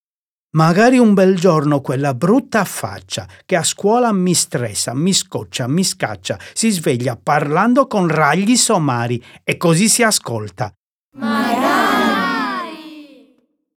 La sequenza completa e continua delle 23 quartine, coi loro cori a responsorio, è offerta in coda al libro con un QR-code.